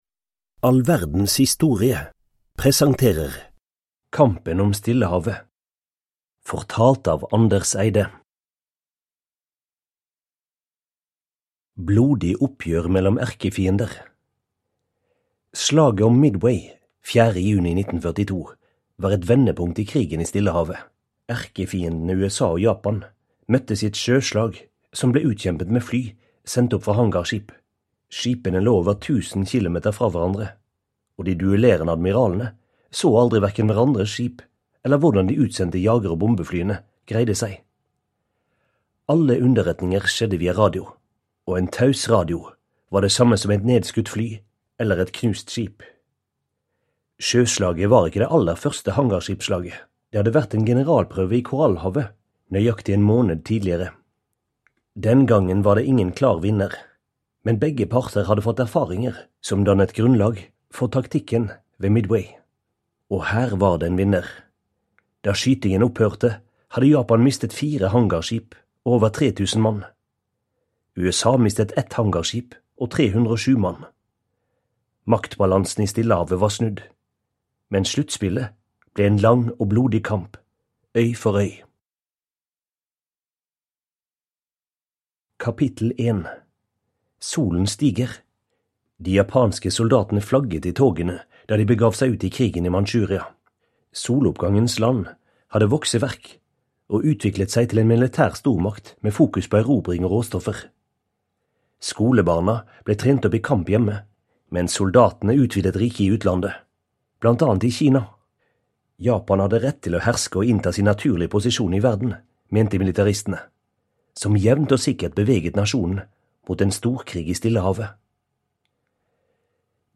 Kampen om Stillehavet (ljudbok) av All verdens historie